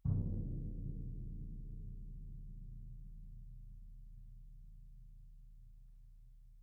Orchestral Bass
bdrum3_ppp_rr1.mp3